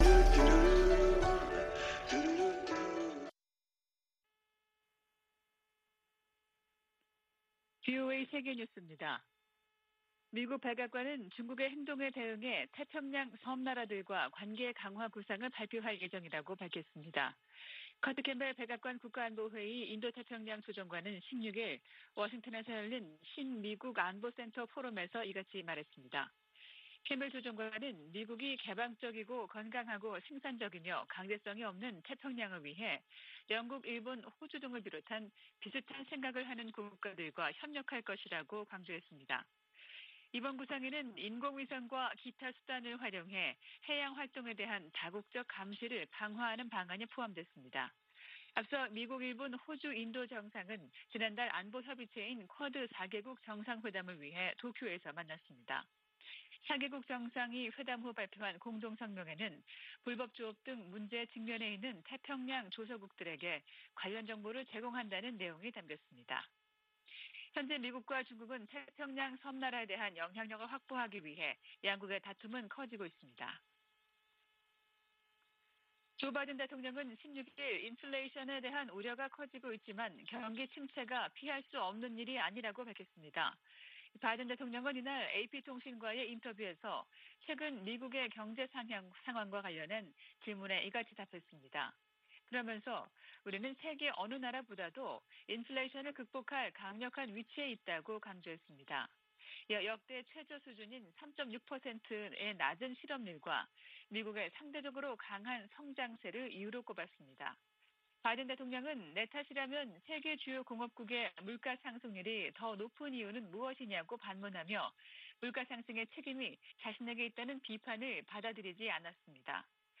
VOA 한국어 아침 뉴스 프로그램 '워싱턴 뉴스 광장' 2022년 6월 18일 방송입니다. 미 국무부가 대화와 외교로 북한 핵 문제를 해결한다는 바이든 정부 원칙을 거듭 밝혔습니다. 북한의 7차 핵실험 가능성이 계속 제기되는 가운데 미국과 중국이 이 문제를 논의하고 있다고 백악관 고위 당국자가 밝혔습니다.